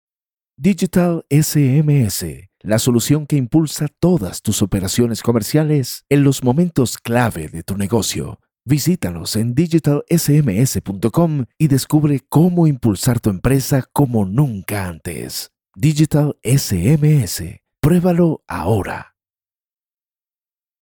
Talent with histrionic skills. Kind, paternal, dynamic and jovial voice.
1011Corporate.mp3